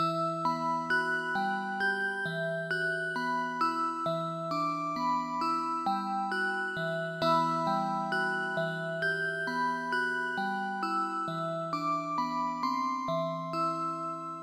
描述：C小调陷阱弹奏出精彩的节拍
标签： 133 bpm Trap Loops Bells Loops 2.43 MB wav Key : C
声道立体声